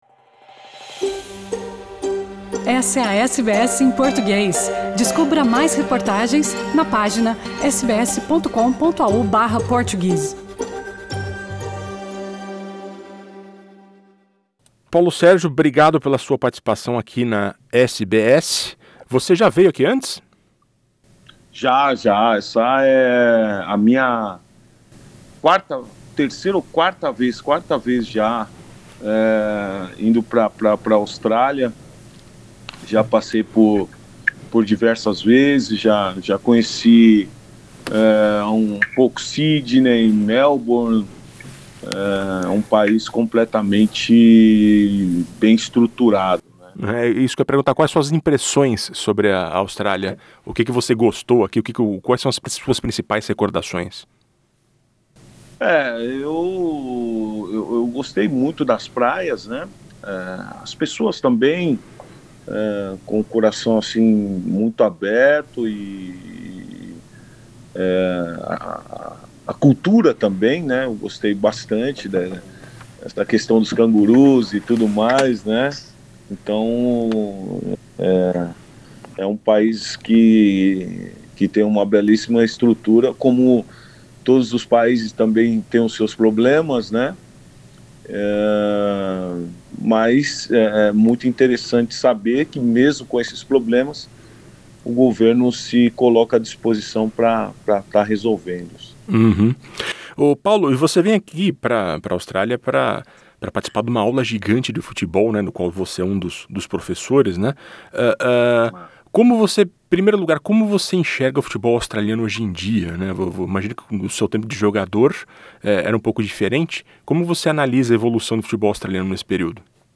Conversamos com o tetracampeão Paulo Sergio, um dos boleiros que estão na Austrália para o recorde da maior aula de futebol do mundo